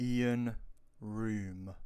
Ian Roome (/rm/